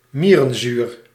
Ääntäminen
France (Île-de-France): IPA: /a.sid fɔʁ.mik/